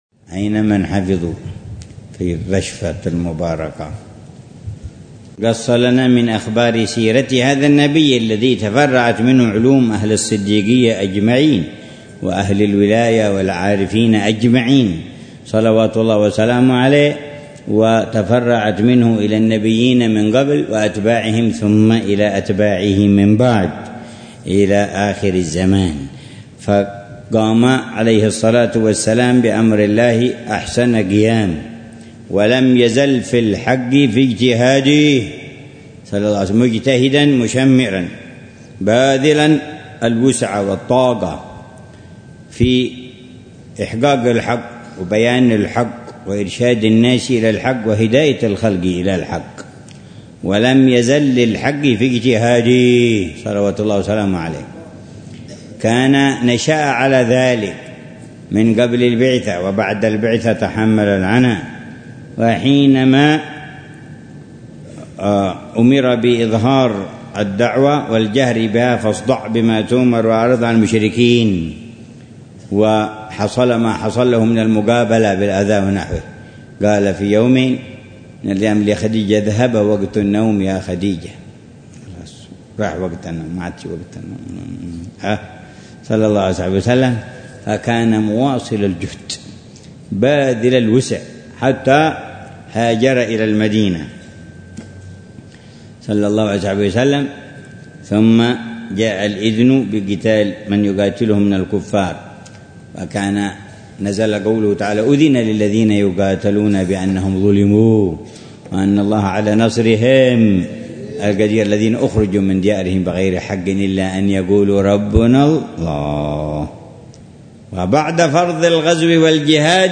شرح الحبيب عمر بن محمد بن حفيظ لرشفات أهل الكمال ونسمات أهل الوصال.